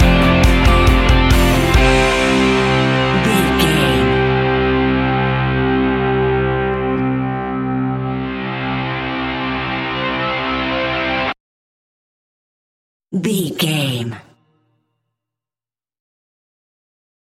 Ionian/Major
energetic
driving
heavy
aggressive
electric guitar
bass guitar
drums
indie pop
uplifting
instrumentals
piano
organ